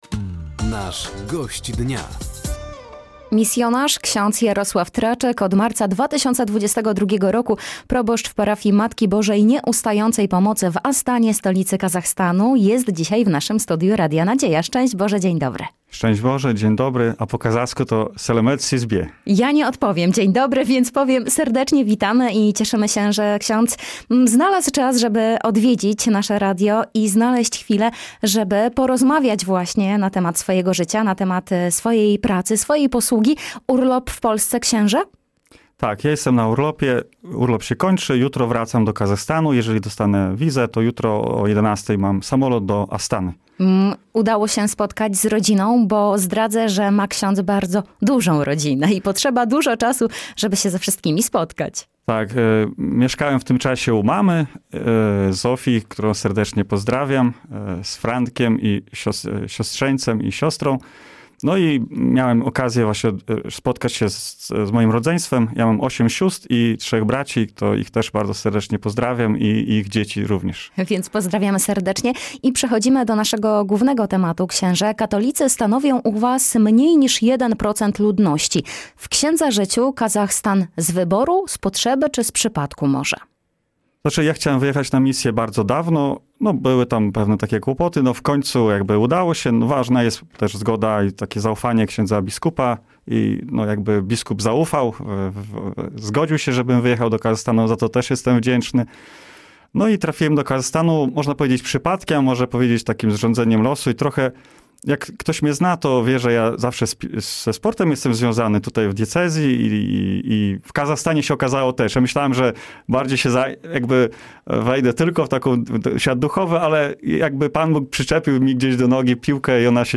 Duchowny na antenie Radia Nadzieja opowiedział o codziennym życiu i posłudze w Kazachstanie.